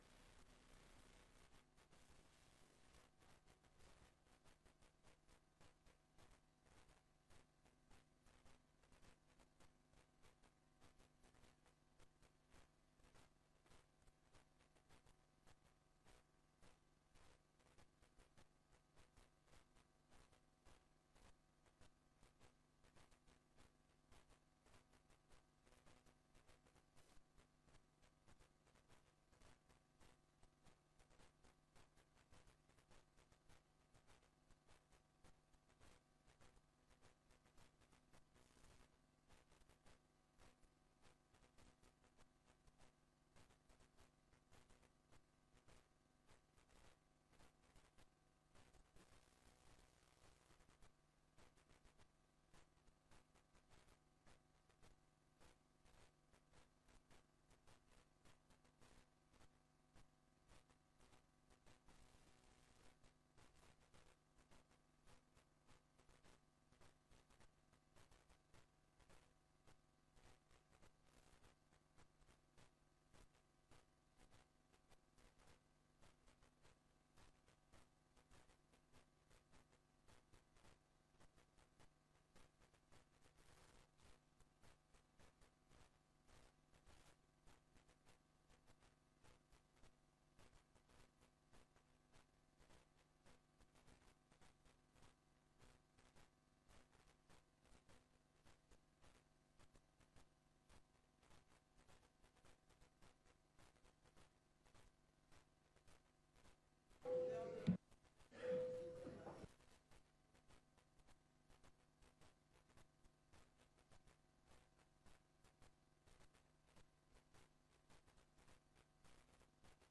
Raadsbijeenkomst 23 september 2025 19:00:00, Gemeente Tynaarlo